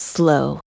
Ripine-Vox_Skill2.wav